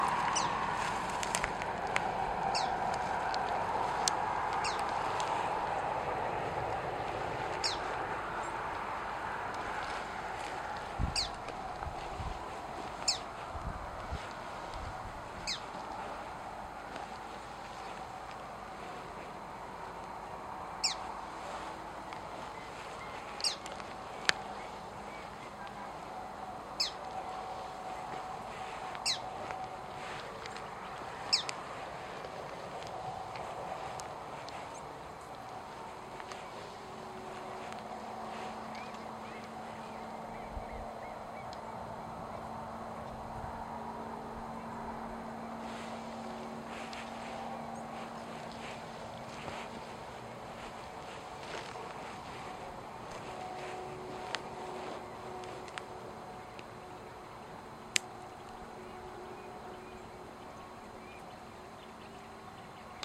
Inambú Montaraz (Nothoprocta cinerascens)
Nombre en inglés: Brushland Tinamou
Localidad o área protegida: Trancas
Condición: Silvestre
Certeza: Vocalización Grabada
inambu-montaraz-mp3.mp3